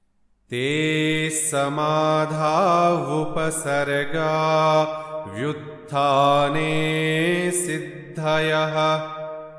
Sutra Chanting